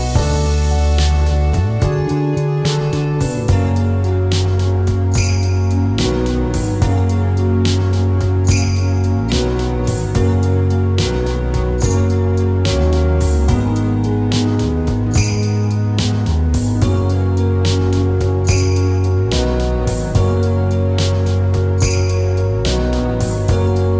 Minus Guitars Rock 4:31 Buy £1.50